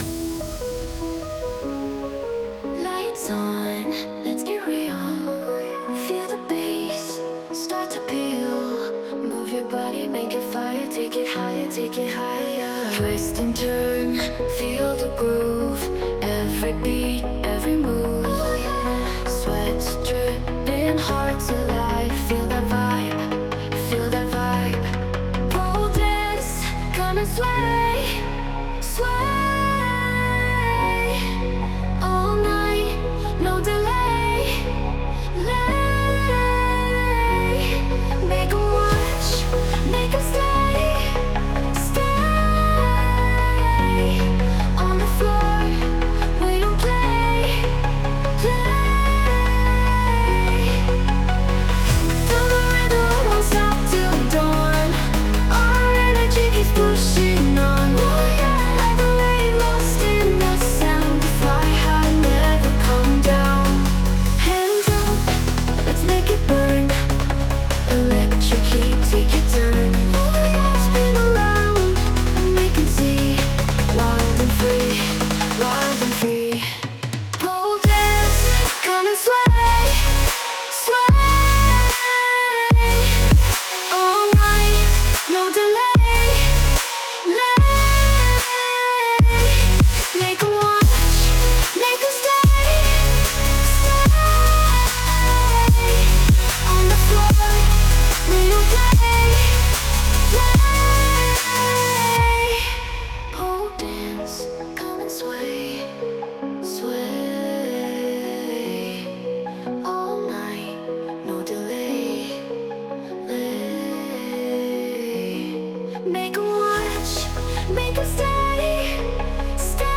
An energetic EDM track with fast tempo and powerful beats. Catchy melodies and dynamic basslines enhance pole dance moves, creating an electrifying atmosphere perfect for the studio.
歌詞も生成されています。